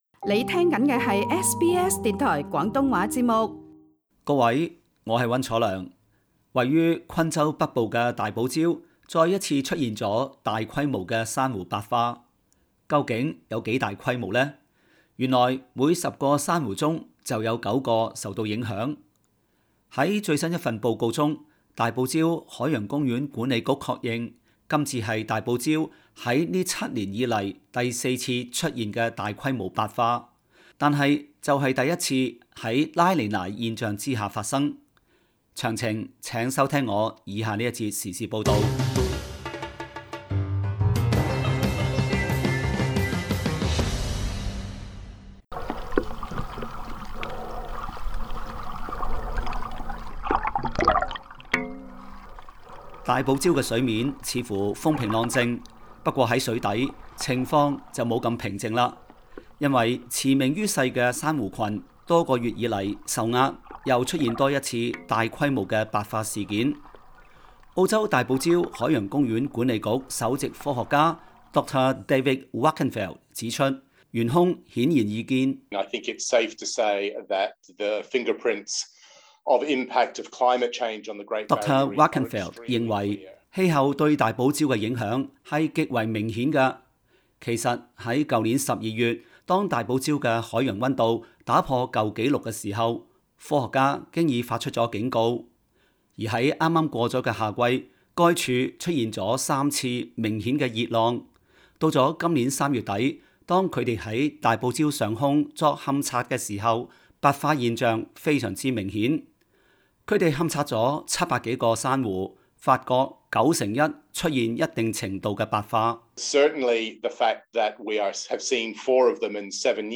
时事报道